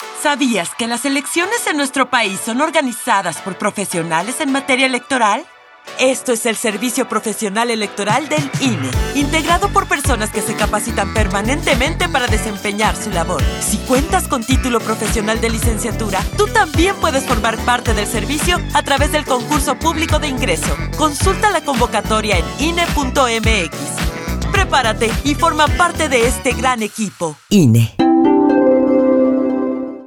➜ Etapas del Concurso Público spot de radio Tu navegador no soporta la reproducción de audio.
Spot-Radio.mp3